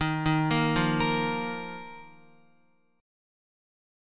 Ebsus2 chord